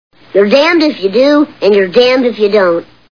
The Simpsons [Bart] Cartoon TV Show Sound Bites